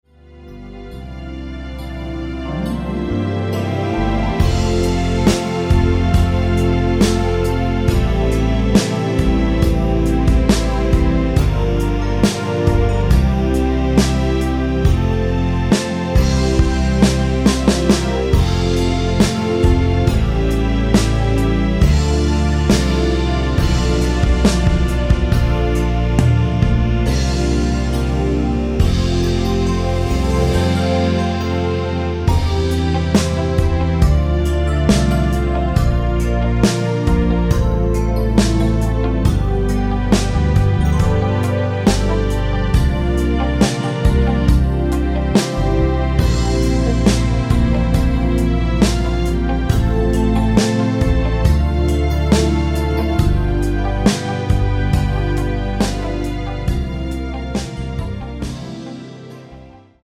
고음질 MR 다운, 코러스MR, 축가MR, 영상MR, 맞춤MR제작, 비회원7일간 무제한 다운로드
◈ 곡명 옆 (-1)은 반음 내림, (+1)은 반음 올림 입니다.